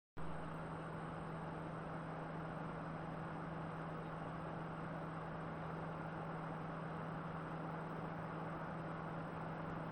Helikopter im Anflug!
Es ist in jedem Fall das Netzteil was den Lärm macht.
Der Krach kommt von unten!